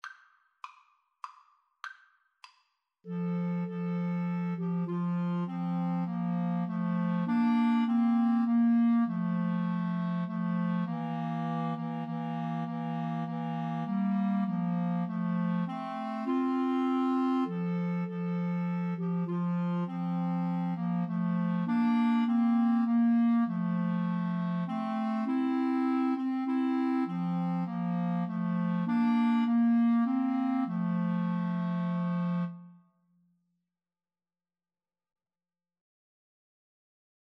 3/4 (View more 3/4 Music)
Clarinet Trio  (View more Easy Clarinet Trio Music)